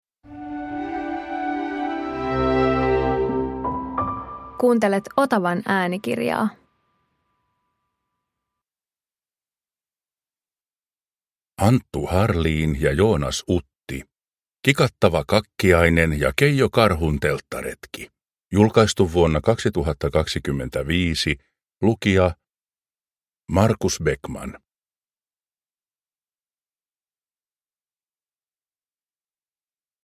Kikattava Kakkiainen ja Keijo Karhun telttaretki – Ljudbok